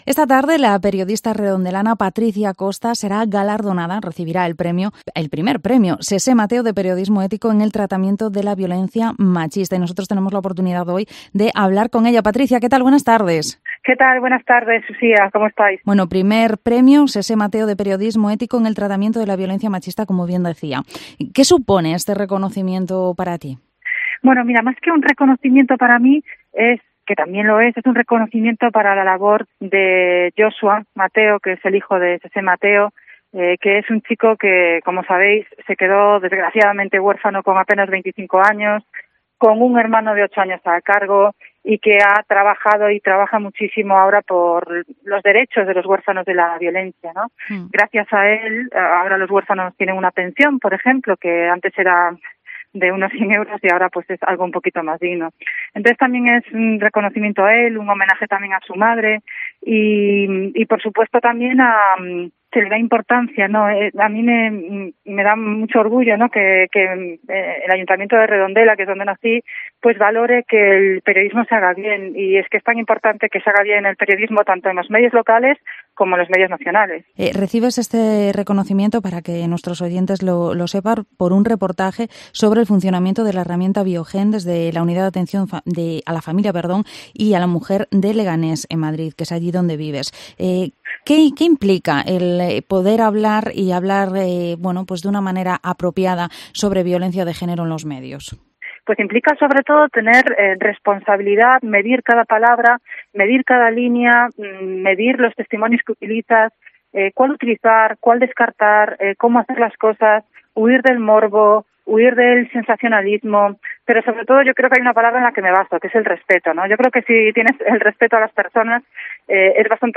Área Metropolitana Vigo Entrevista